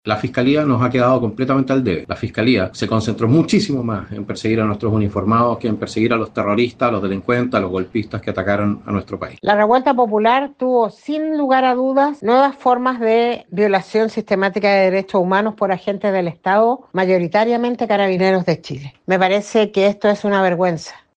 El diputado Johannes Kaiser (PNL) sostuvo que es un agravio contra los funcionarios policiales acusados injustamente. Por otro lado la diputada Lorena Pizarro (PC), lo catalogó como impunidad.